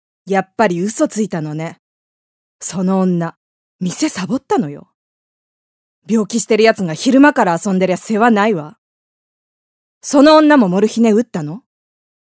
ボイスサンプル
セリフA